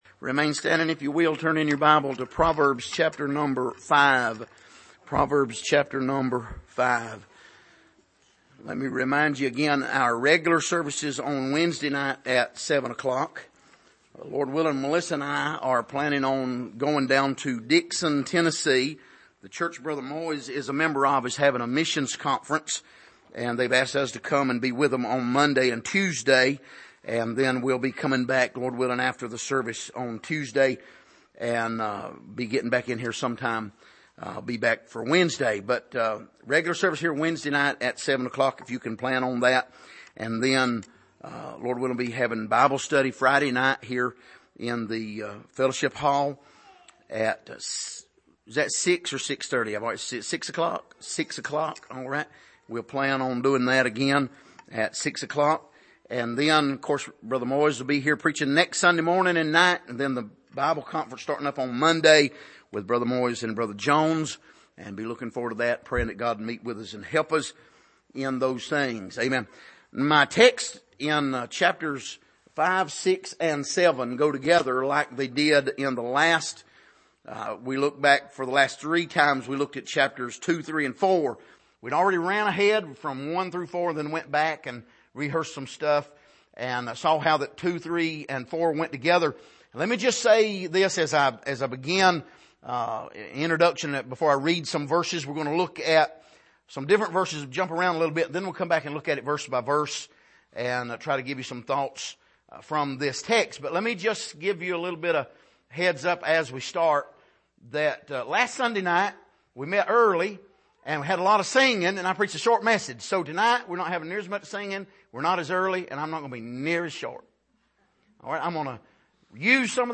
Passage: Proverbs 5:3-8 Service: Sunday Evening